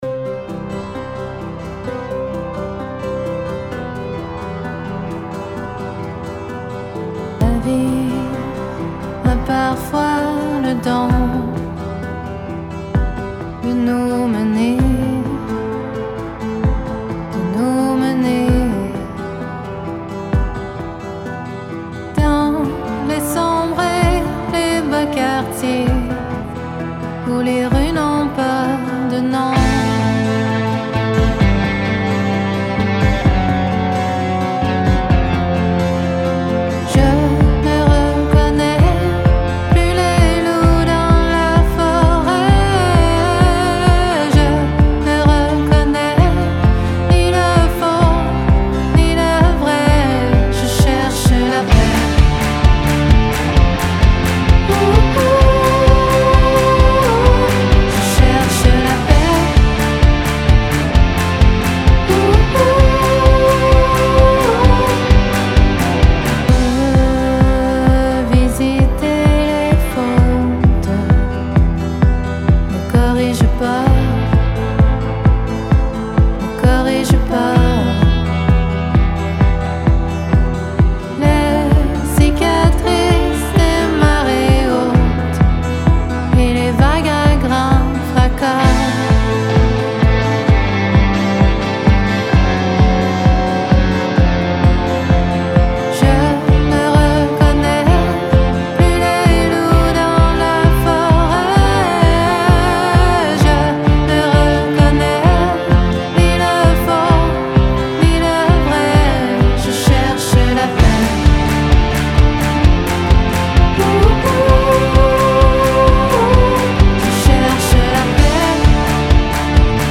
tout en se laissant porter par des courants folk et country.
guitare
batterie